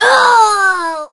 jess_die_03.ogg